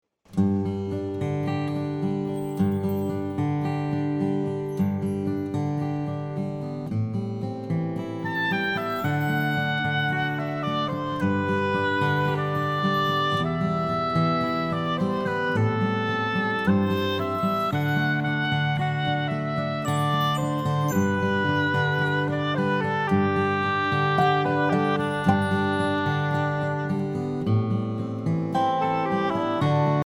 Listen to a sample of the instrumenal track.